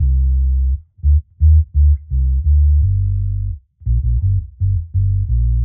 Index of /musicradar/dub-designer-samples/85bpm/Bass
DD_JBass_85_C.wav